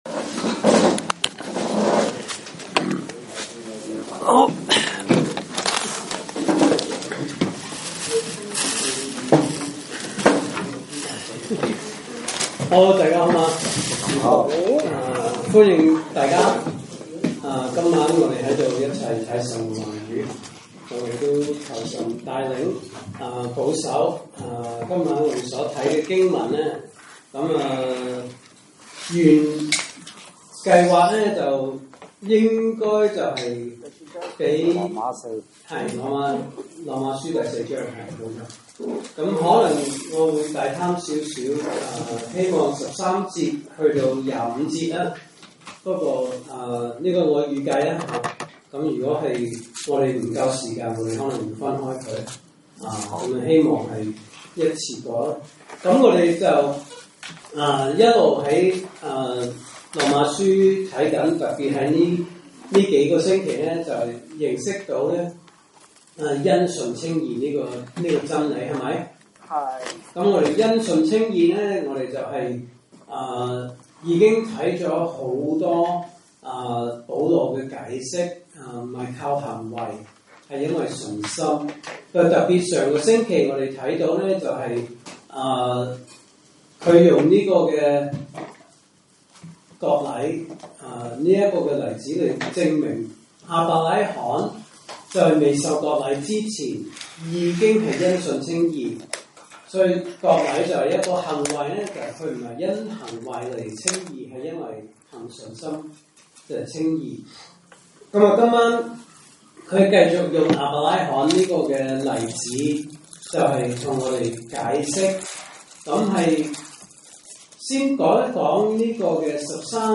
來自講道系列 "查經班：羅馬書"